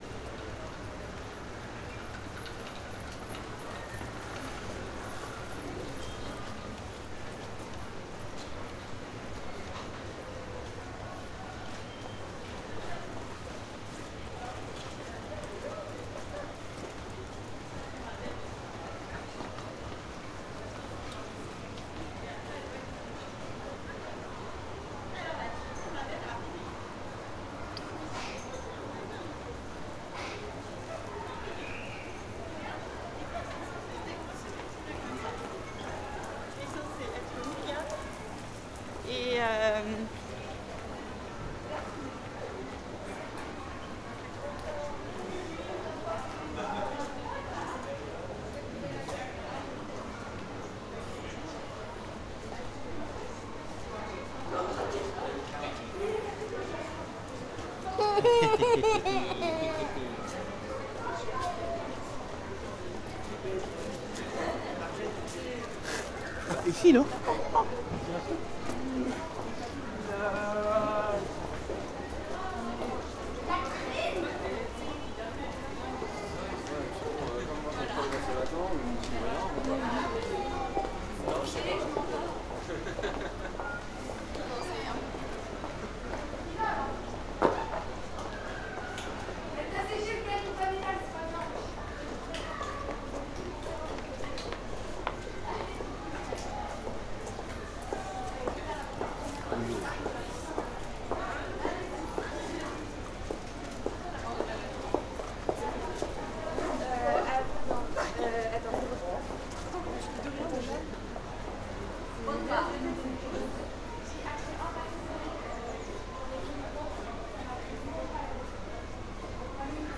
Paris_street_large1.R.wav